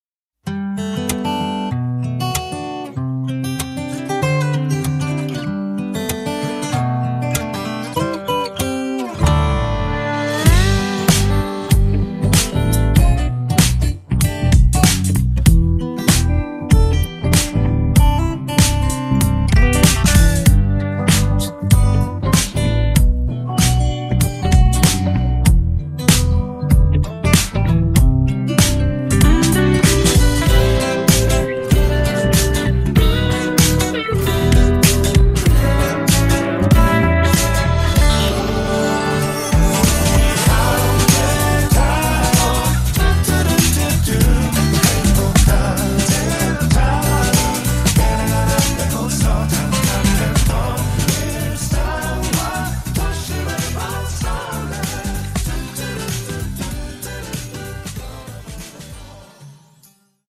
음정 -1키 3:18
장르 가요 구분 Voice MR